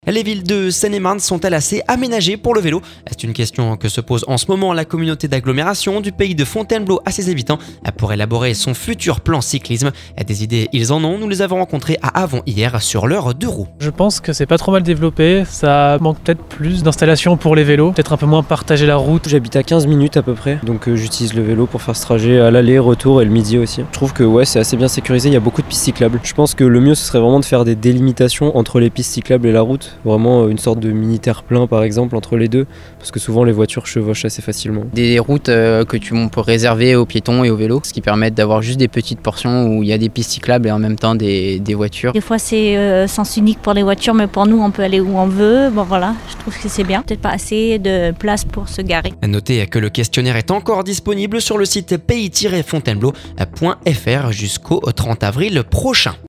Des idées ils en ont, nous les avons rencontrés à Avon hier sur leur deux roues…